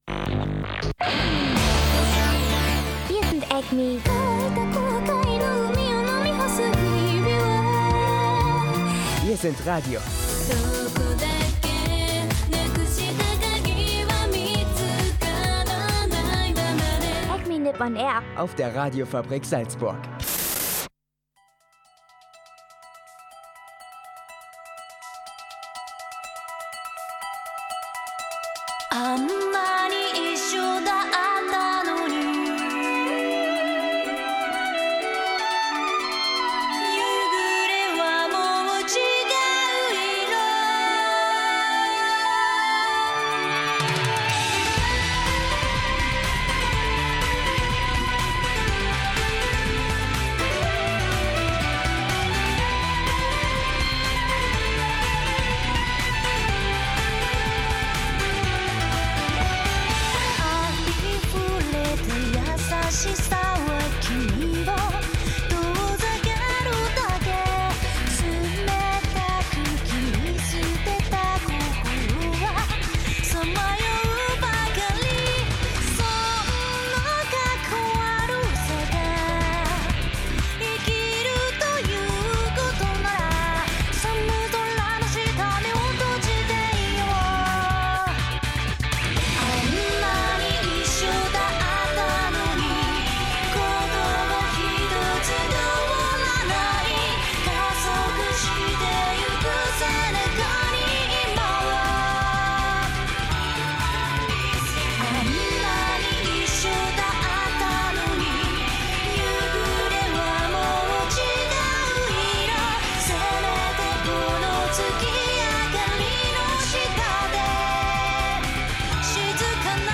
Dieses Mal ganz normal mit Anime-Tipp, Anime-Neuigkeiten, News auf Japan und viel abwechslungsreicher Musik